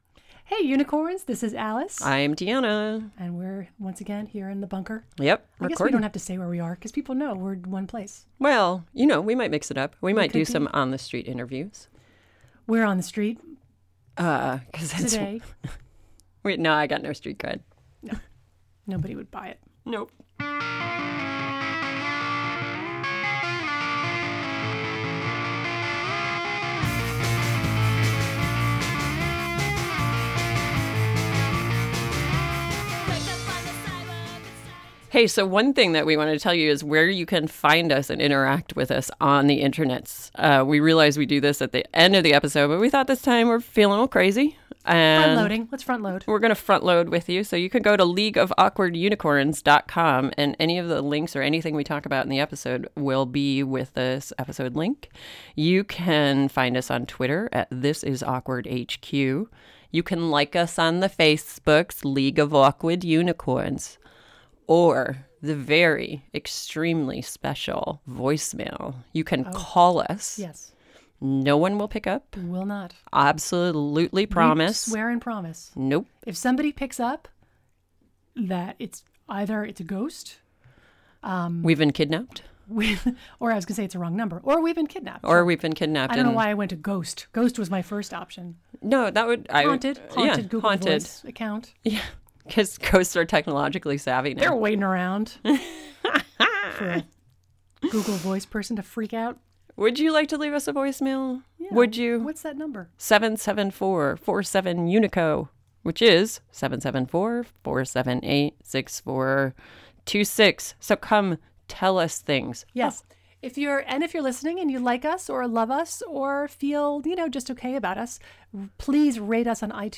We’ve nabbed our first interview!